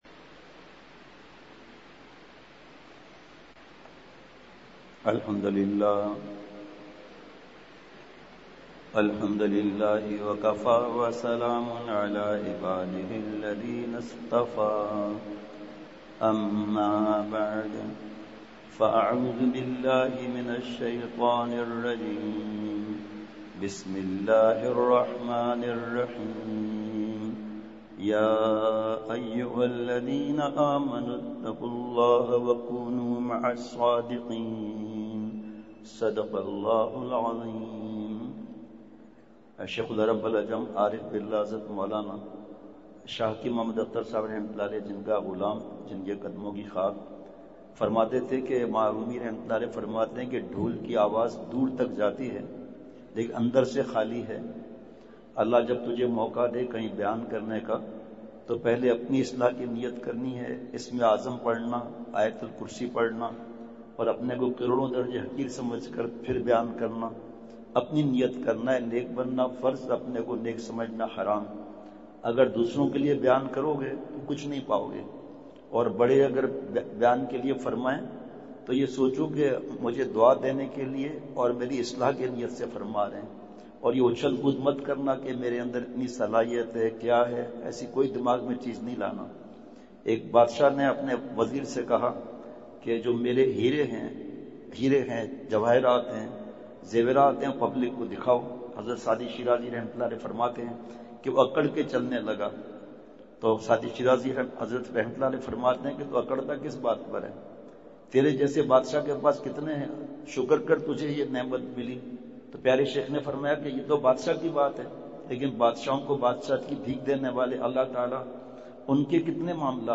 بمقام: جامعہ خیرالمدارس ملتان